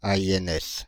Ääntäminen
Ääntäminen France (Île-de-France): IPA: /a.i.ɛn.ɛs/ Haettu sana löytyi näillä lähdekielillä: ranska Käännös Konteksti Substantiivit 1.